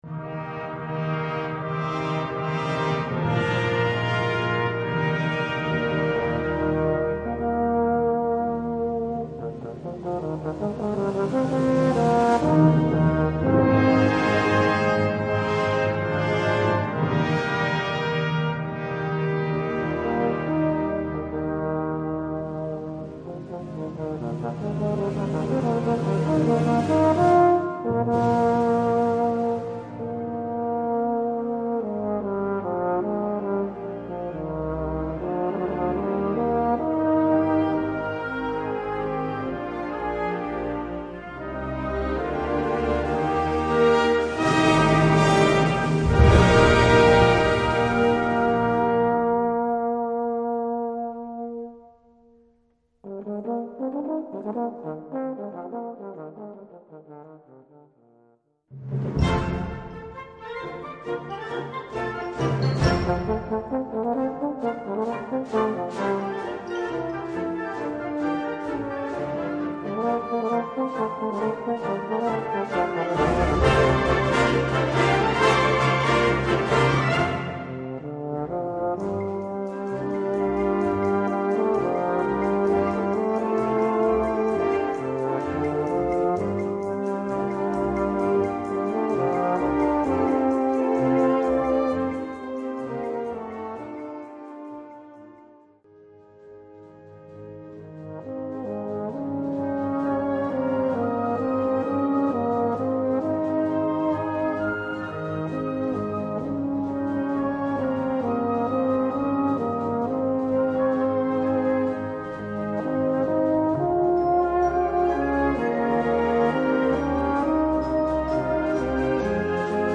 Gattung: Solo für Euphonium
Besetzung: Blasorchester